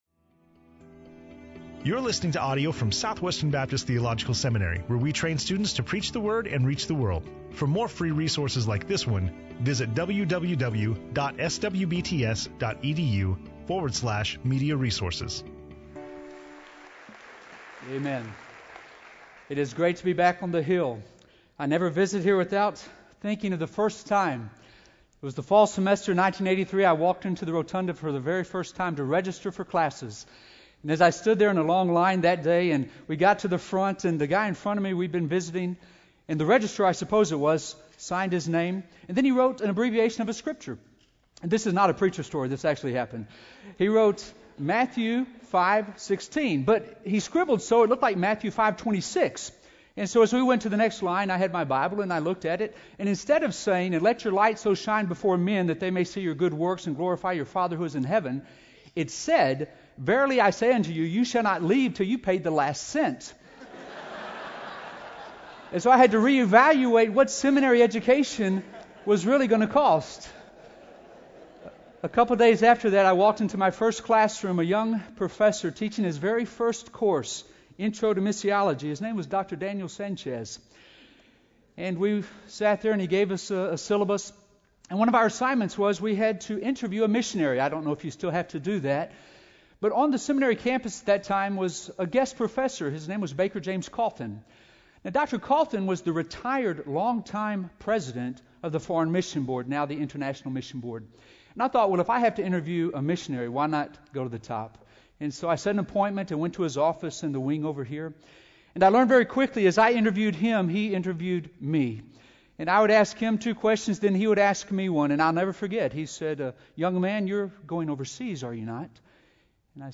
SWBTS Chapel